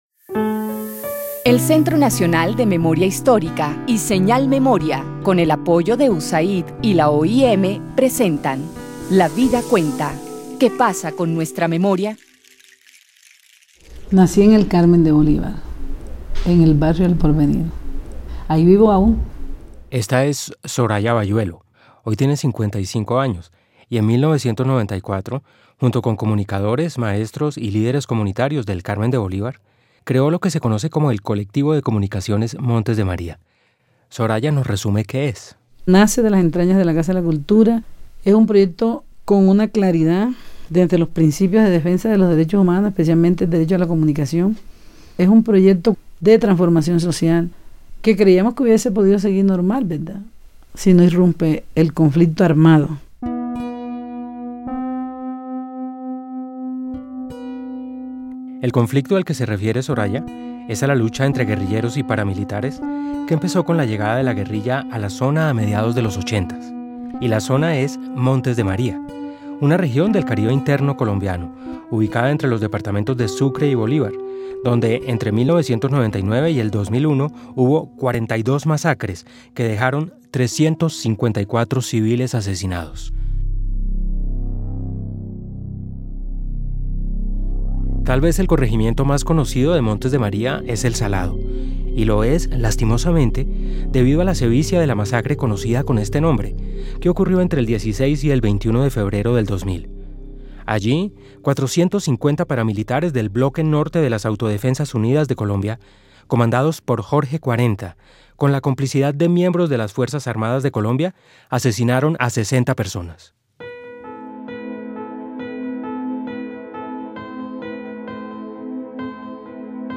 (tomado de la fuente) Audiencia (dcterms:audience) General Descripción (dcterms:description) Serie radial basada en el informe ¡Basta ya! Colombia: memorias de guerra y dignidad.